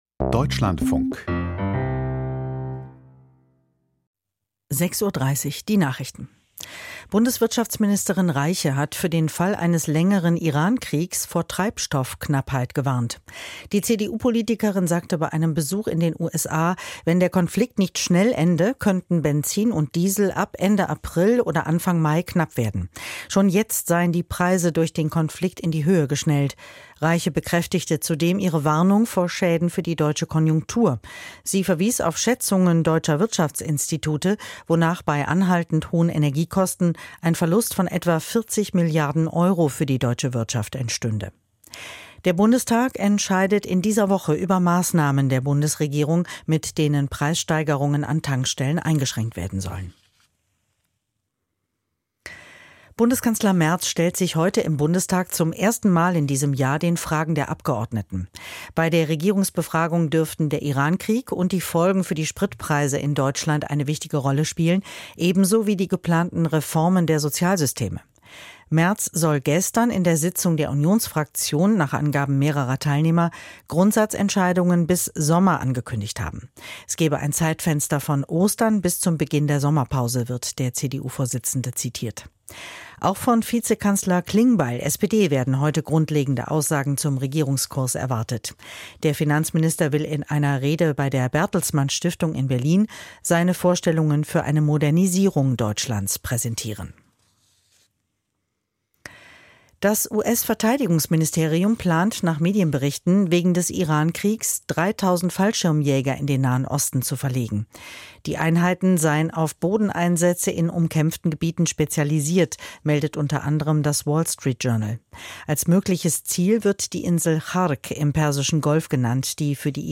Aus der Deutschlandfunk-Nachrichtenredaktion.